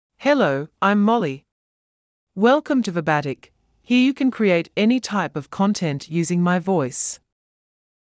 Molly — Female English (New Zealand) AI Voice | TTS, Voice Cloning & Video | Verbatik AI
Molly is a female AI voice for English (New Zealand).
Voice sample
Female
Molly delivers clear pronunciation with authentic New Zealand English intonation, making your content sound professionally produced.